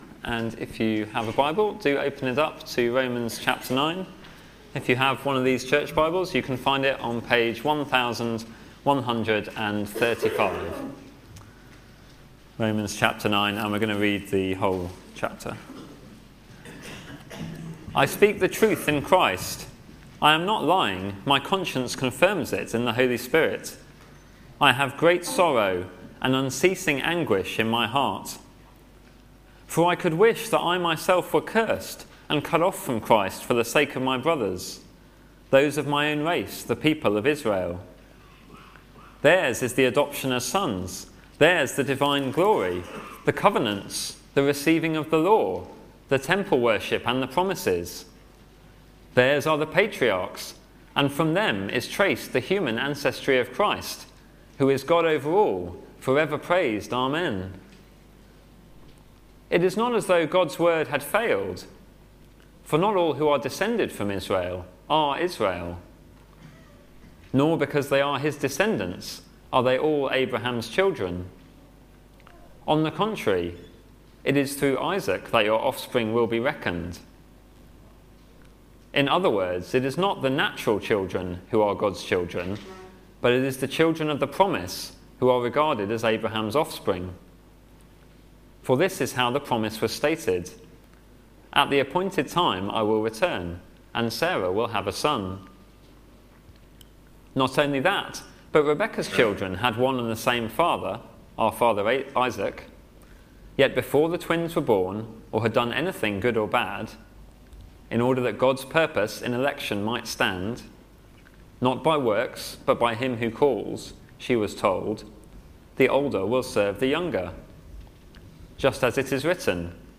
Media for Sunday Service on Sun 11th Jan 2015 10:00
Sermon